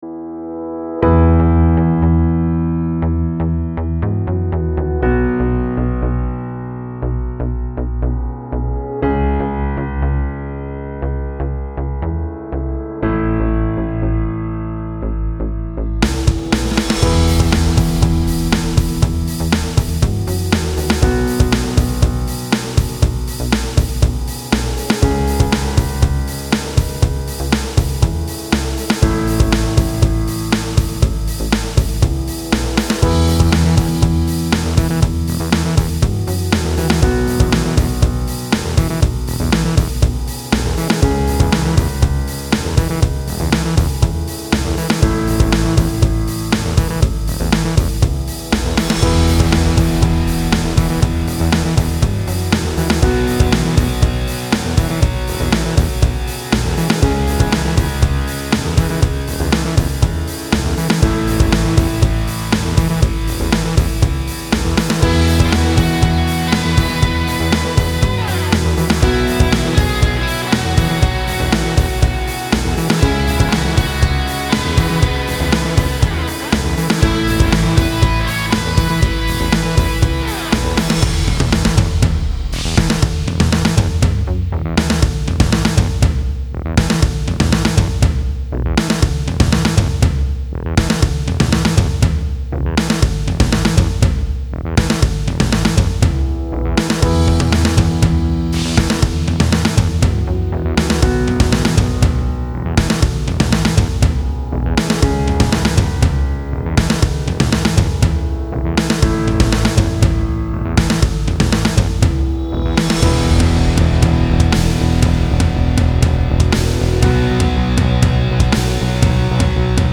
Style Style Corporate, Rock
Mood Mood Bright, Uplifting
Featured Featured Bass, Drums, Electric Guitar +2 more
BPM BPM 120